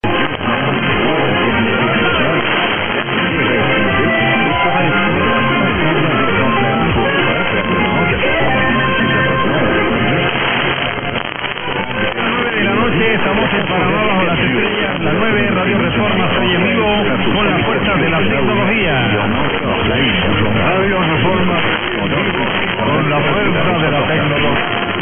860 | PANAMA | HOL55, R. Reforma, Chitre, JAN 15 0200 - Radio Reforma ID; well over Reloj Cuban.